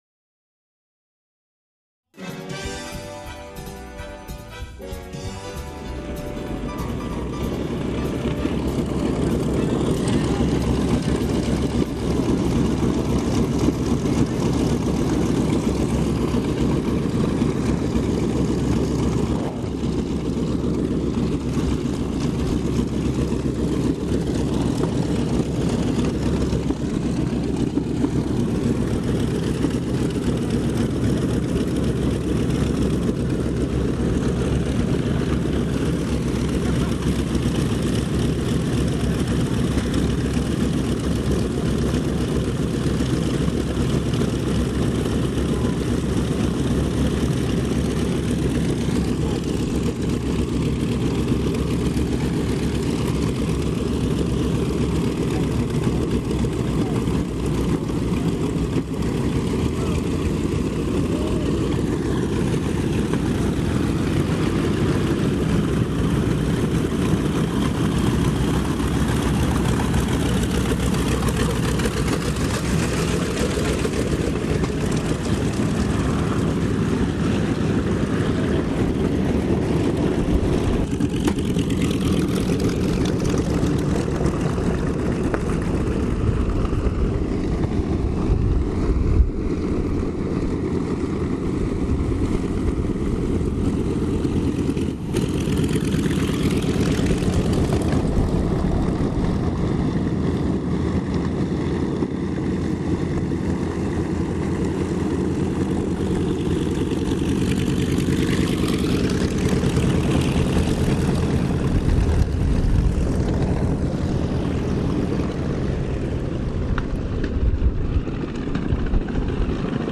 What does it sound like to have twelve World War II era B-25 "Mitchell" Bombers taxi over your head, form for an en masse run-up, take off one after the other and then, fly overhead in mass formation?
I usually carry a small but very powerful digital recorder in my fanny pack. I had it turned on during the mass formation taxi and the subsequent take-off activities.
Doolittle Reunion B-25 Sounds
High Quality Digital Audio Recording